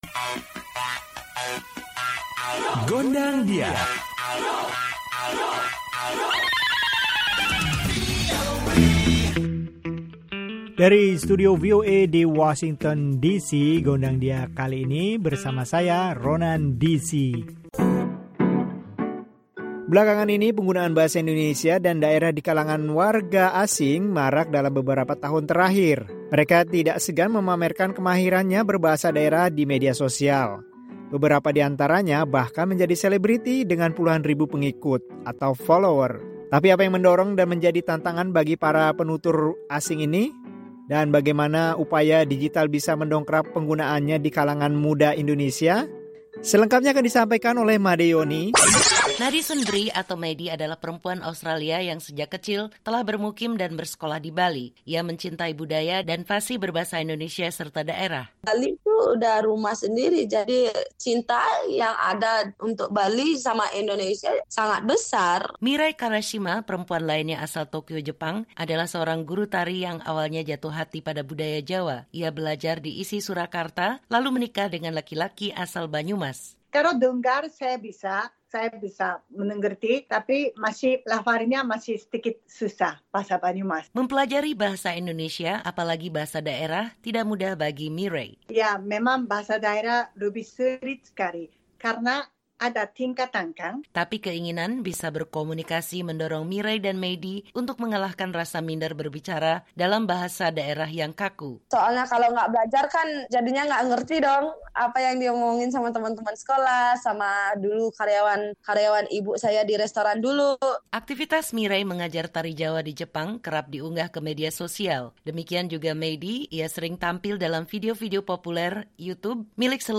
Obrolan bersama beberapa orang asing yang bisa berbicara bahasa daerah dengan fasih.